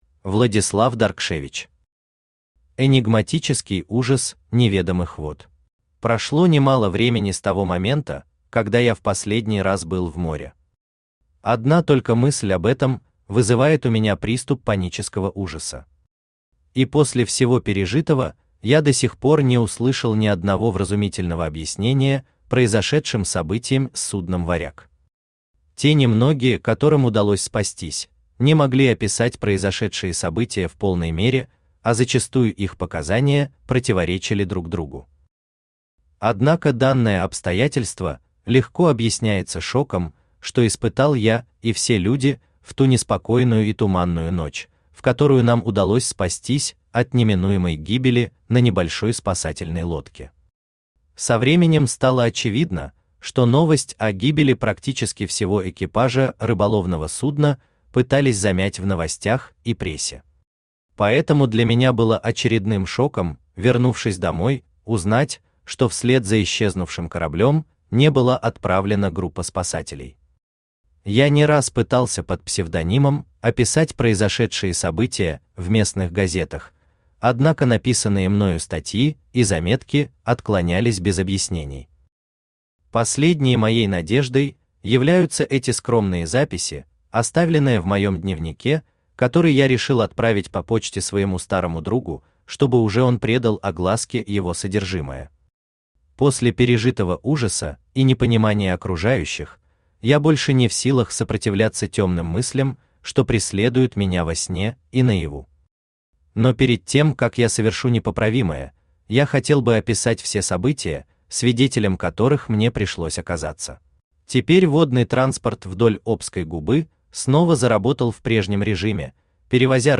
Aудиокнига Энигматический ужас неведомых вод Автор Владислав Даркшевич Читает аудиокнигу Авточтец ЛитРес.